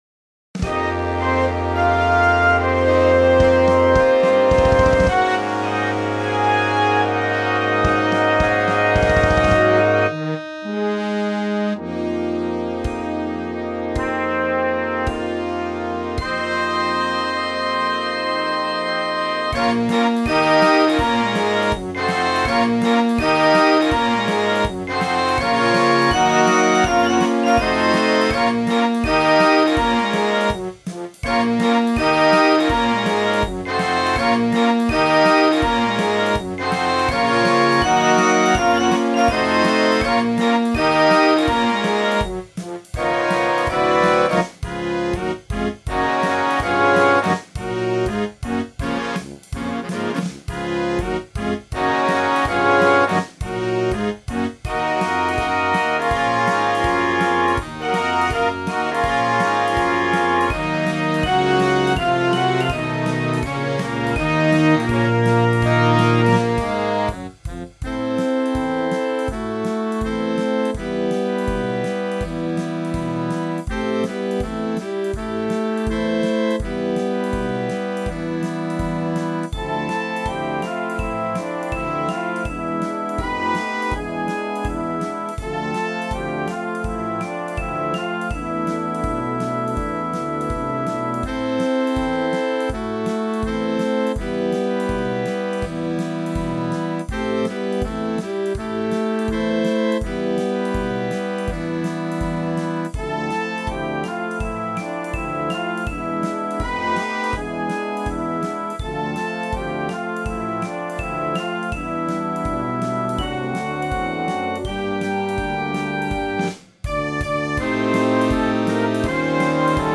Russisches Potpourrie
Blasorchester